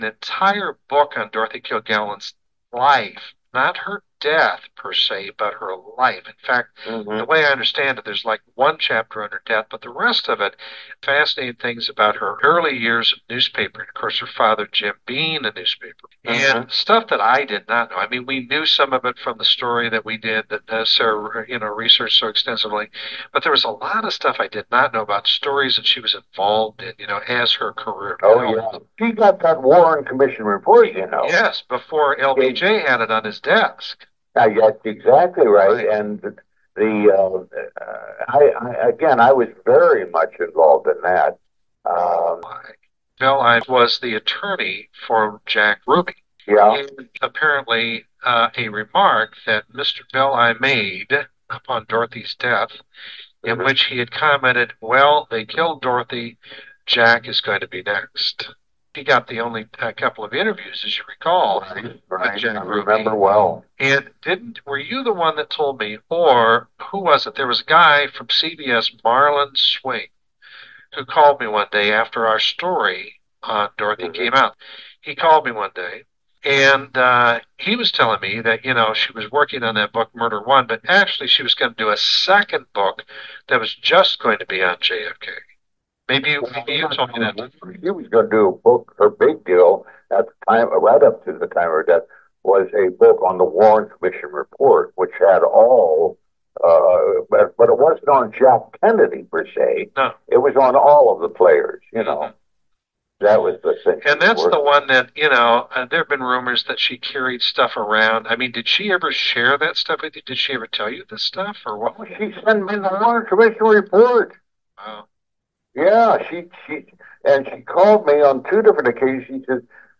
Excerpts of exclusive interviews with the man whom some suspect as